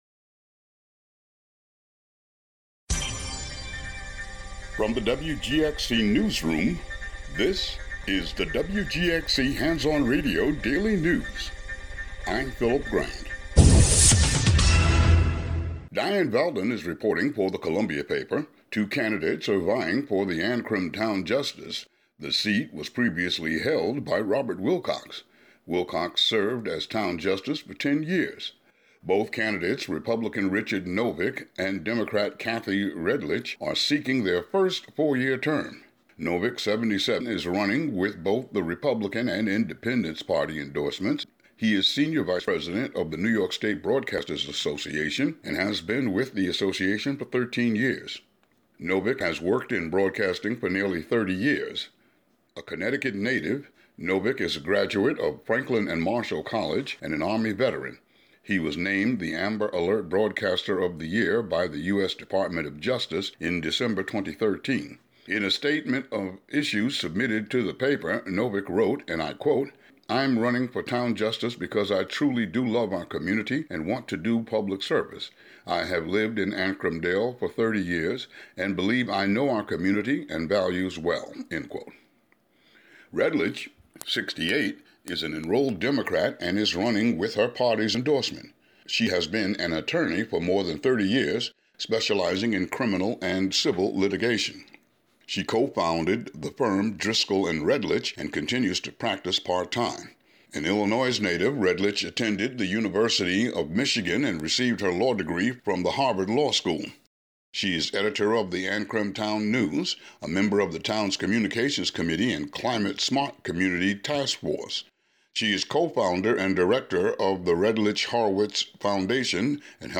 Today's local news.